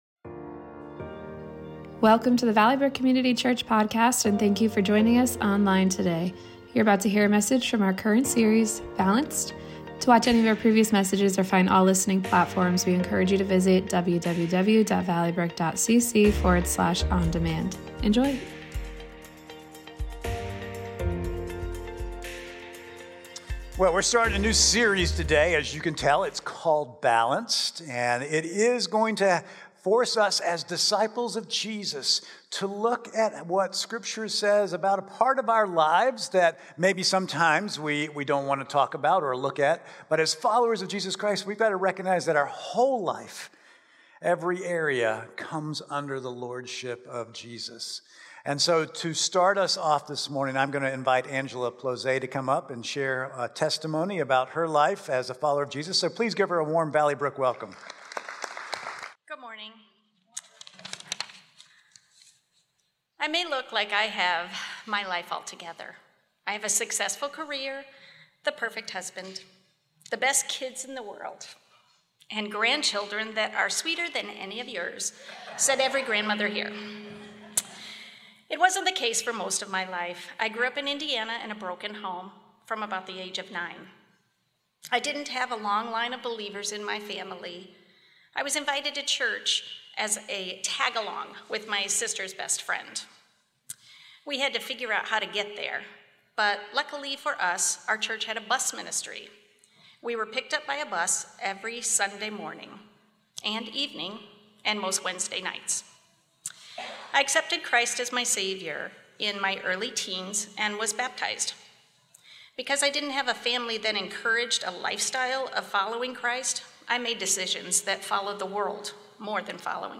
Valley Brook Sermon Podcast